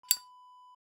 Zippo Open Wav Sound Effect
Description: The sound of a zippo lighter cap opening
Properties: 48.000 kHz 24-bit Stereo
A beep sound is embedded in the audio preview file but it is not present in the high resolution downloadable wav file.
Keywords: zippo, cigarette, lighter, cap, click, flip, open, opening
zippo-open-preview-1.mp3